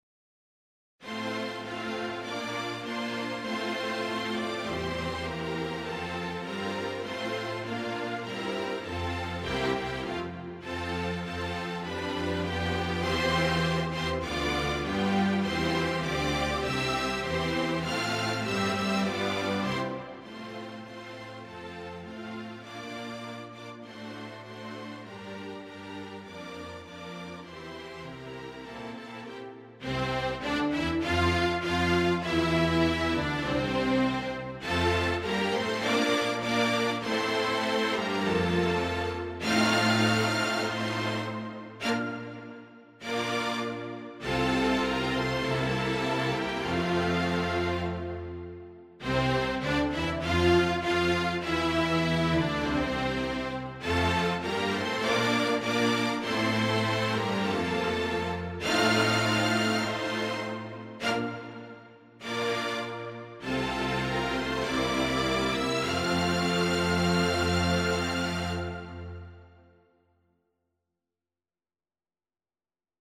synthesised string orchestra version
vivas-schola-regia-string-orchestra.mp3